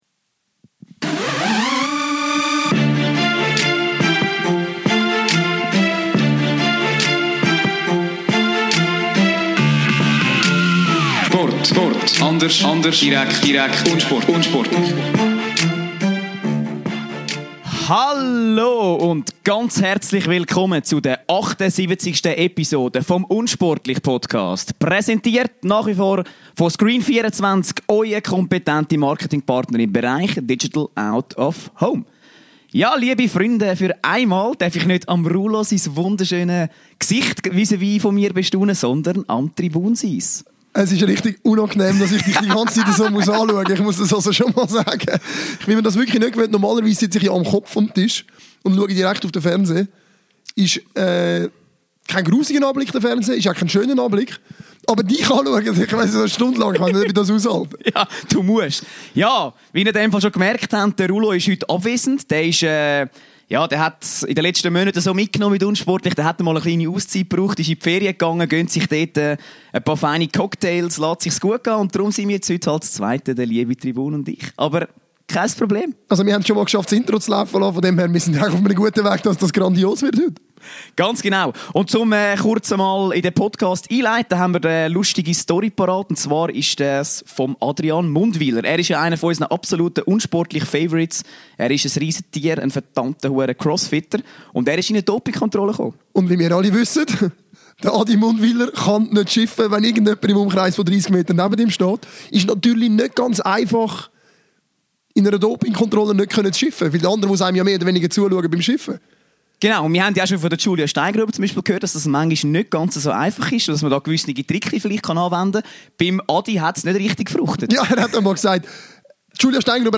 im Keller